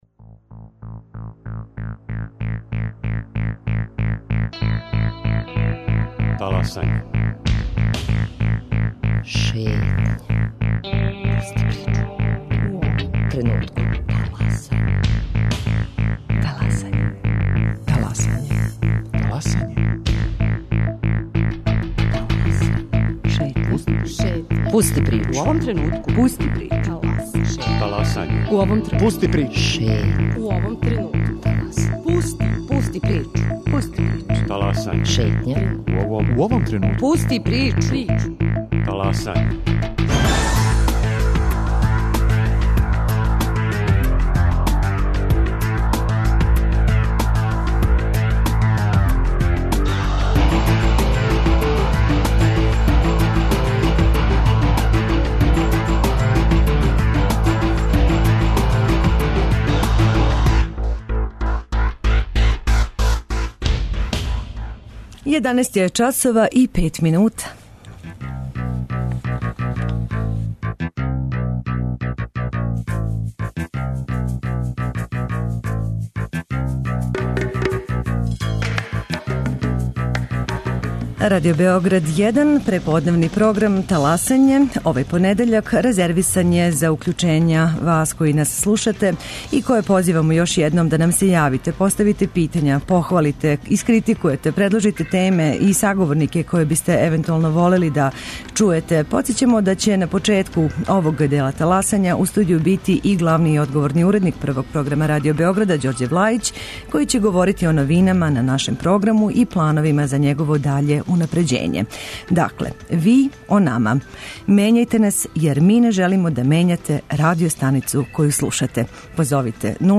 Овај понедељак резервисан је за укључења слушалаца Радио Београда 1, које позивамо да нам се јаве и поставе питања, похвале, критикују, предложе теме и саговорнике које би волели да чују...